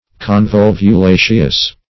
Search Result for " convolvulaceous" : The Collaborative International Dictionary of English v.0.48: Convolvulaceous \Con*vol`vu*la"ceous\, a. [From Convolvus .]
convolvulaceous.mp3